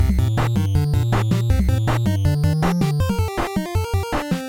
This song is inspired by run and gun shooters on the NES.